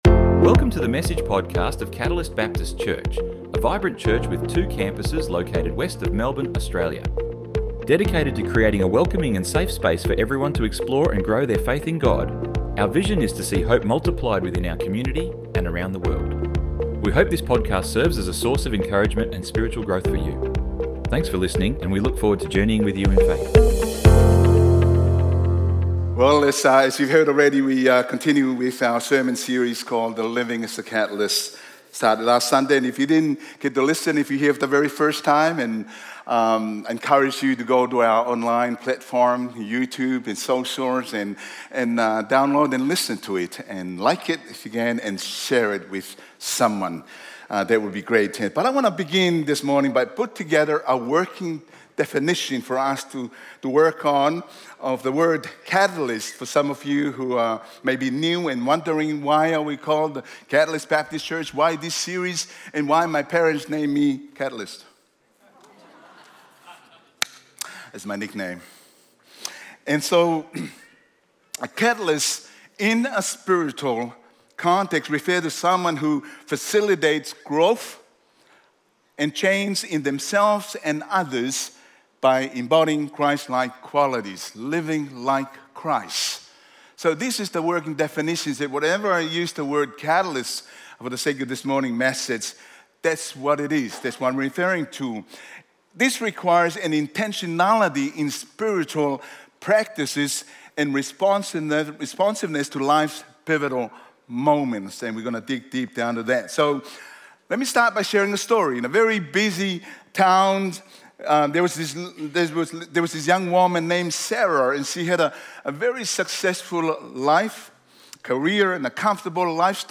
Current Sunday Messages